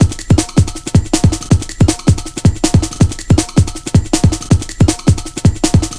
Index of /90_sSampleCDs/Zero-G - Total Drum Bass/Drumloops - 1/track 16 (160bpm)